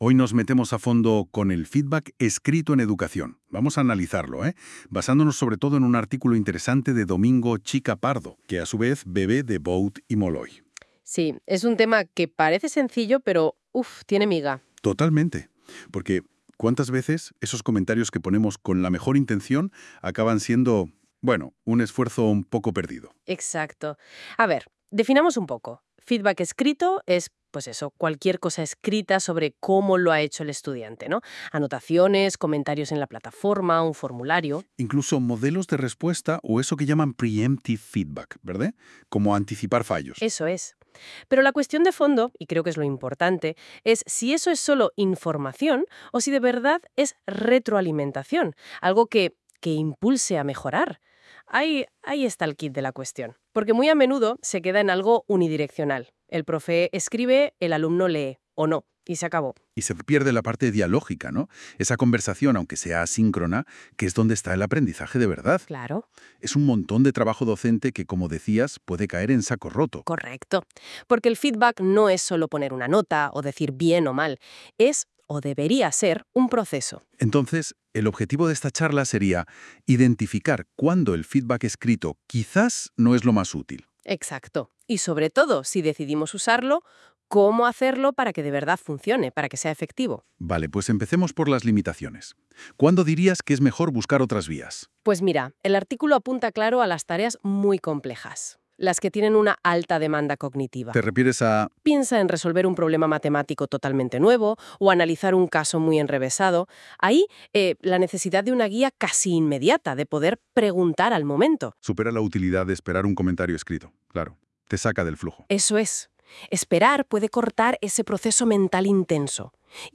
Audio de la entrada generado con NotebookLM.